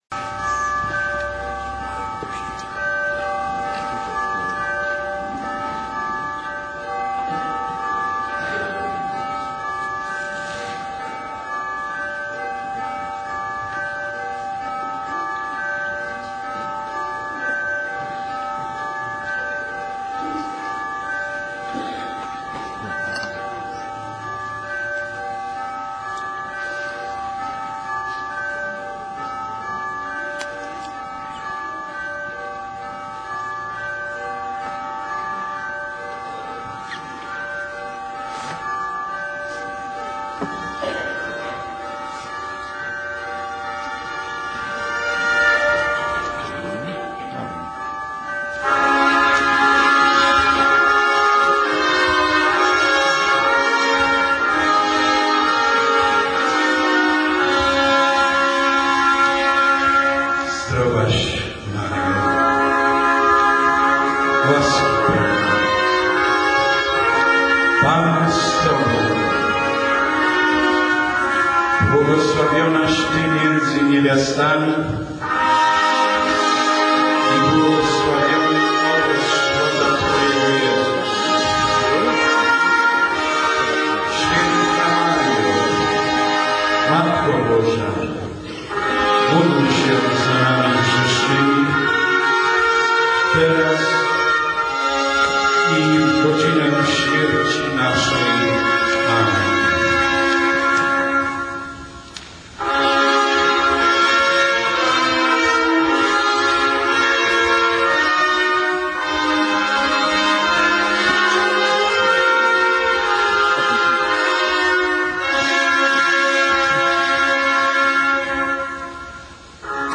40-Lecie Iskier Iż Polacy nie Gęsi Zamiast tłumaczyć każdemu z osobna, pomyślałem ,że napiszę Zdjęcia i Wideo z Obchod�w 101-ej Rocznicy Istnienia Sokoła Nr 1 Nagranie Dźwiękowe z Wieczoru Maryjnego